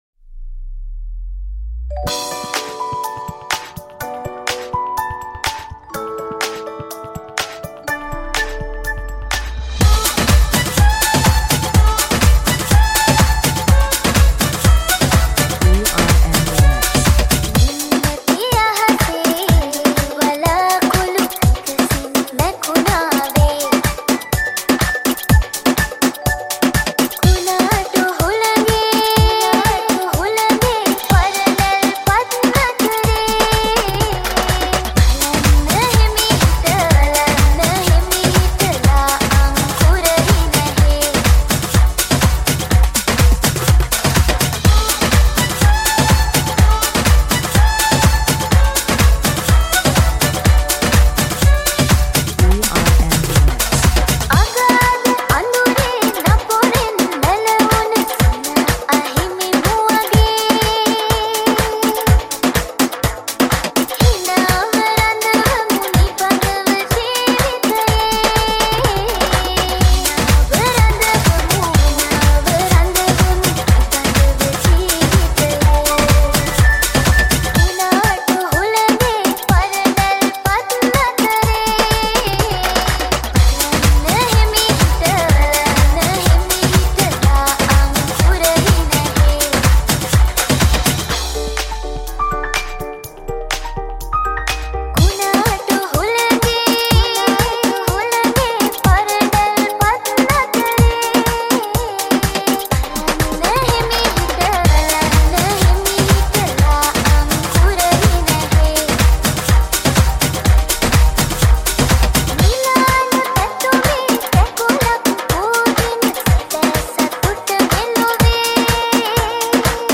Techno House Mix